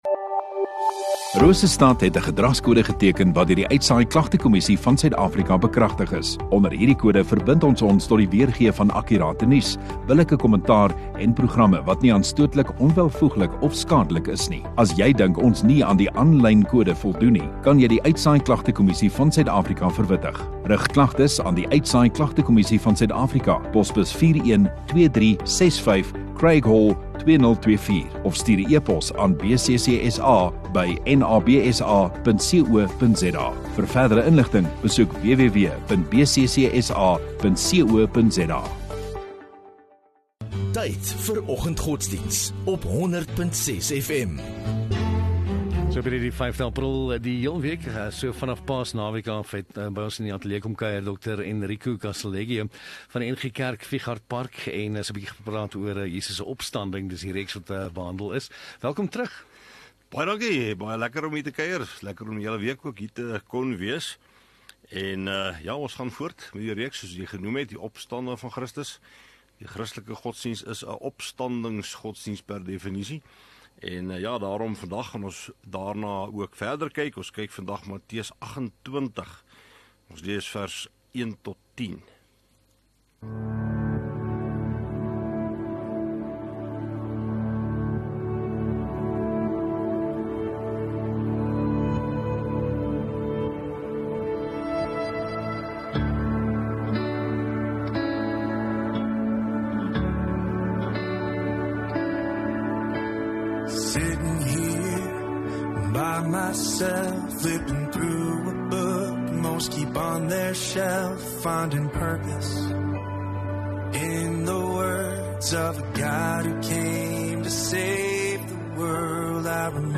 5 Apr Vrydag Oggenddiens